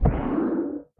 guardian_hit1.ogg